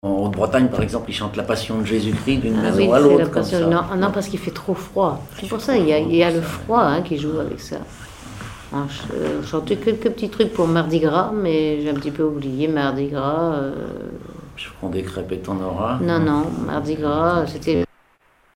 chanteur(s), chant, chanson, chansonnette
Miquelon-Langlade